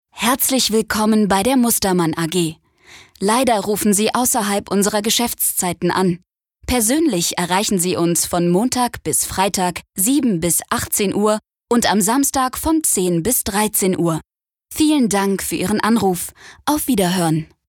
Telefonansage Hochdeutsch (CH)